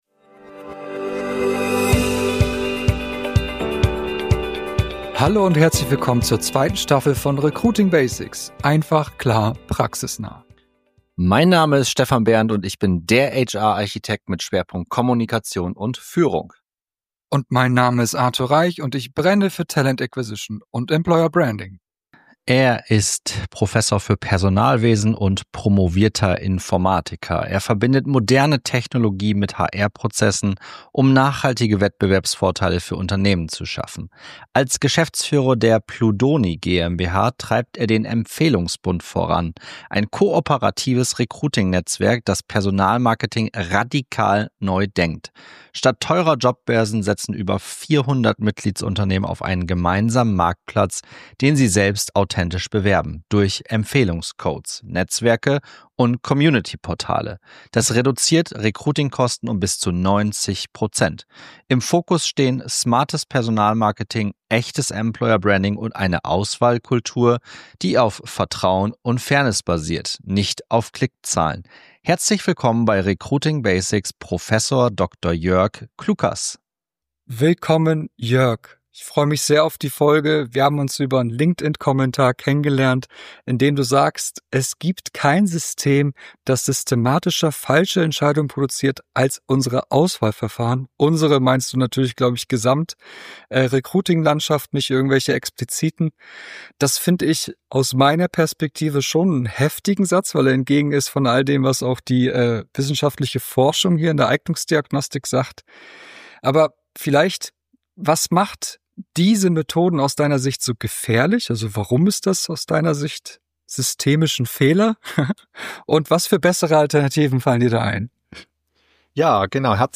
Streitgespräch